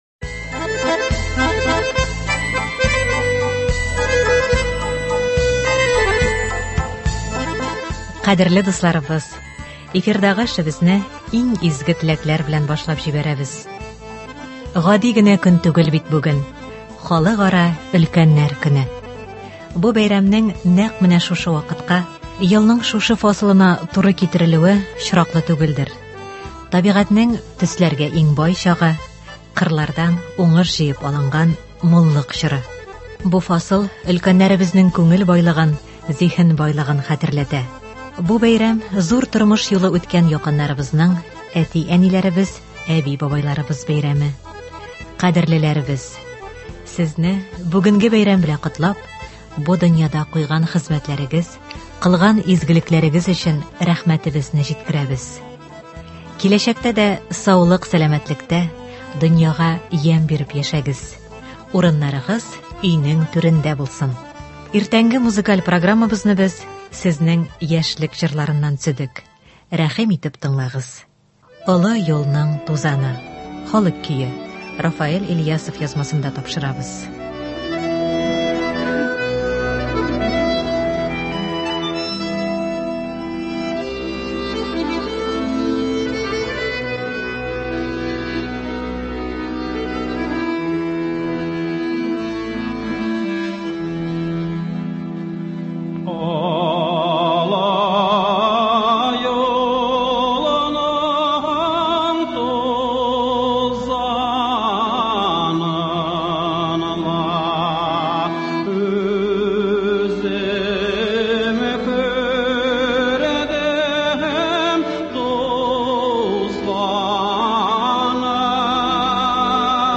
Халыкара өлкәннәр көненә багышланган әдәби-музыкаль композиция (01.10.22)